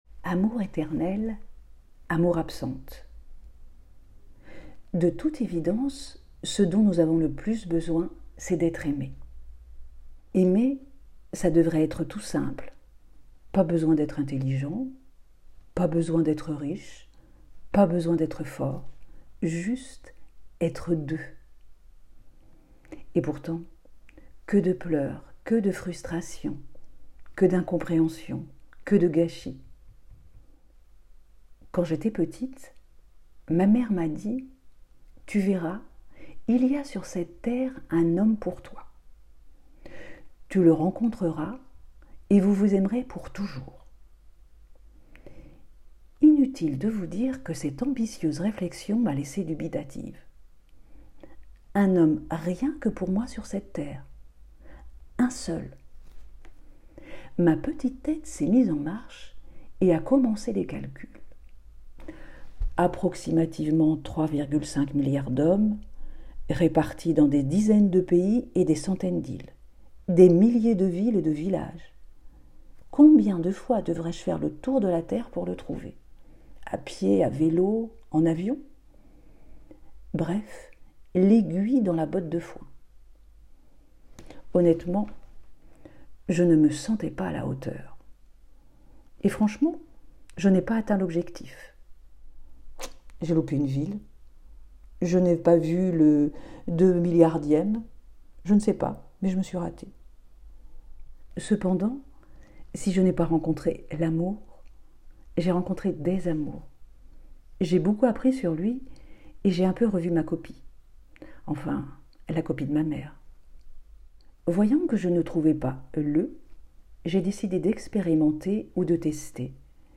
9 Nov 2024 | Article audio